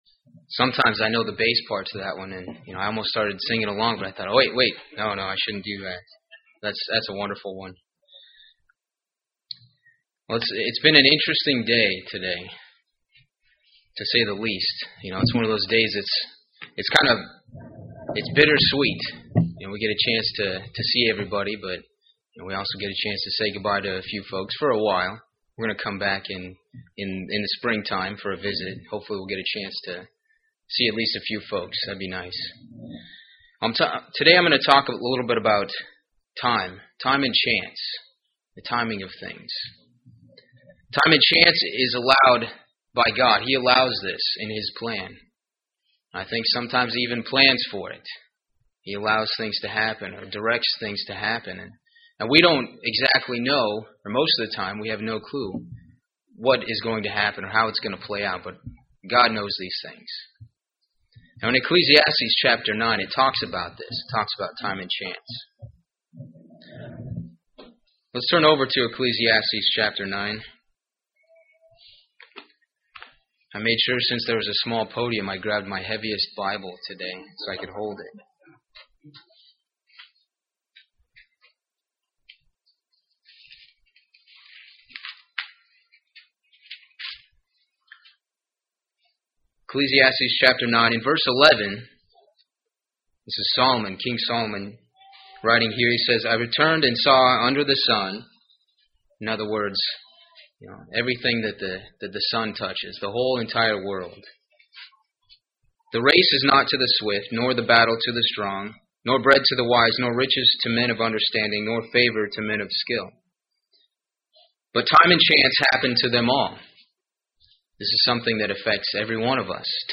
Sermons
Given in Birmingham, AL Gadsden, AL Huntsville, AL